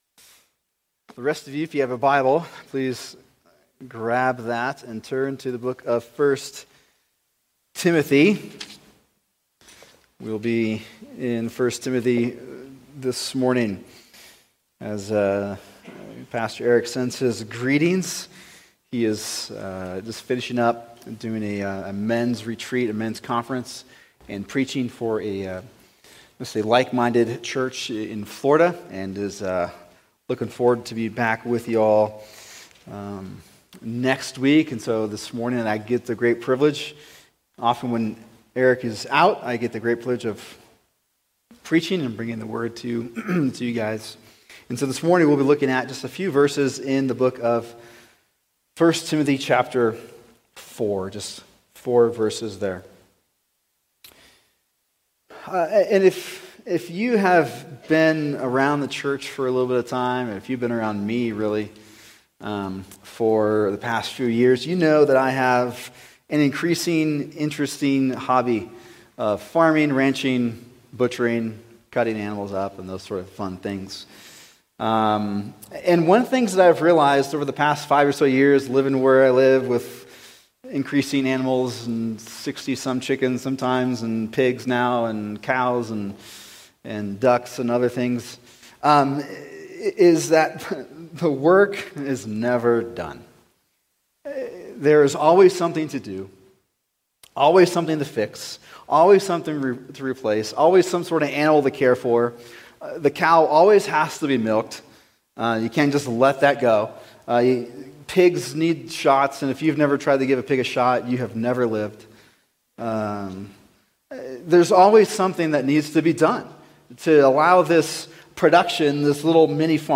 [sermon] 1 Timothy 3:13-16 The Pastor’s Priority | Cornerstone Church - Jackson Hole